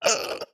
hurt1.ogg
sounds / mob / strider / hurt1.ogg